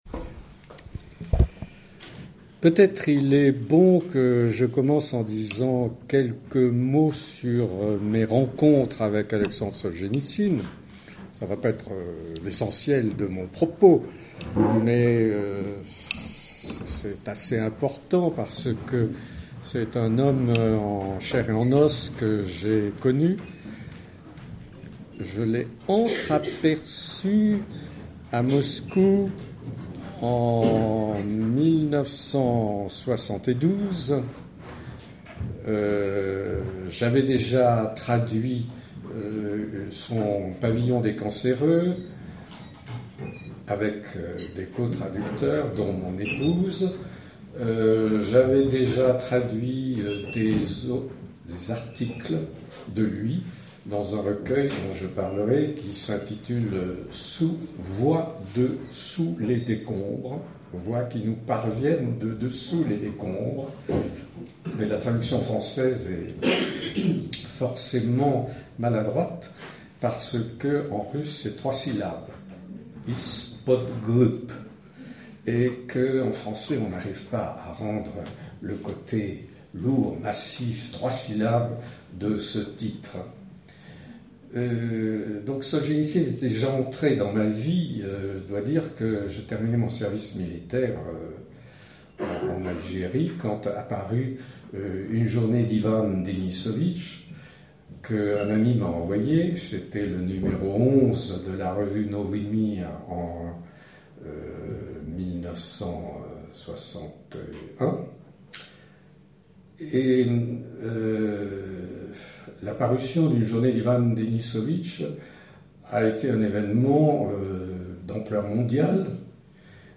Foi qui rassemble, réel qui disperse, chemin poétique de Soljénitsyne - Conférence
Médias Foi qui rassemble, réel qui disperse, chemin poétique de Soljénitsyne - Conférence Foi qui rassemble, réel qui disperse, chemin poétique de Soljénitsyne - Questions/réponse Georges Nivat Universitaire français, historien des idées et slavisant, traducteur spécialiste du monde russe. Conférence donnée dans le cadre des jeudis de Philanthropos